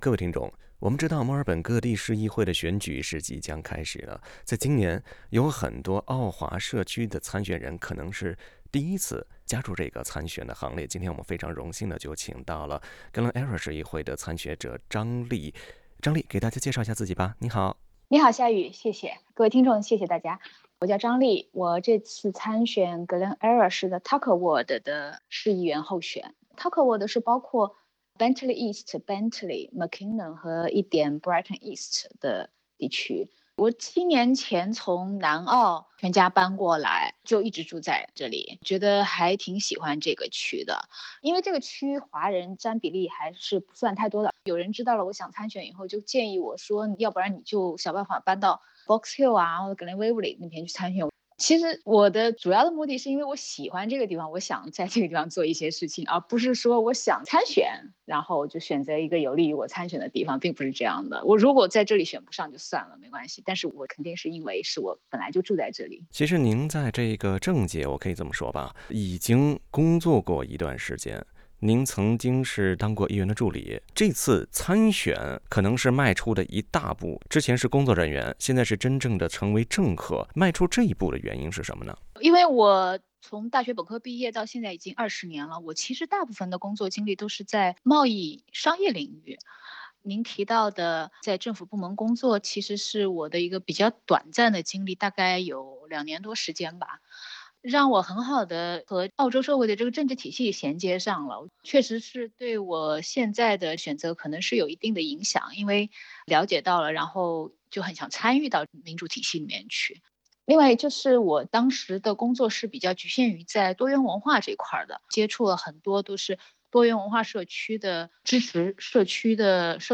（点击图片音频，收听采访）